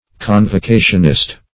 Search Result for " convocationist" : The Collaborative International Dictionary of English v.0.48: Convocationist \Con`vo*ca"tion*ist\, n. An advocate or defender of convocation.